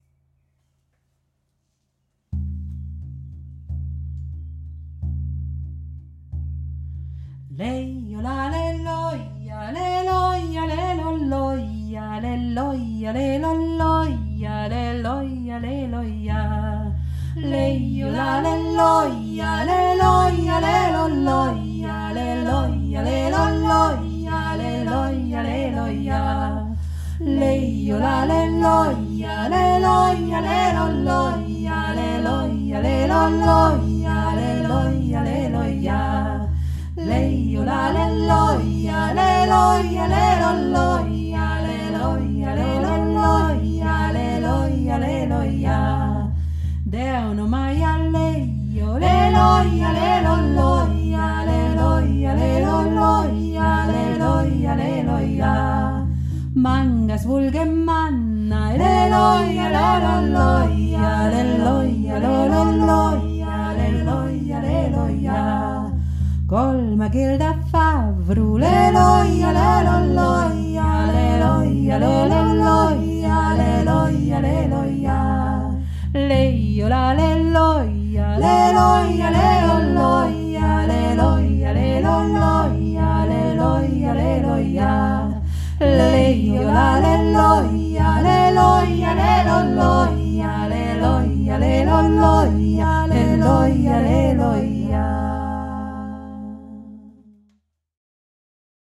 Yoik Deanu Maia - mehrstimmig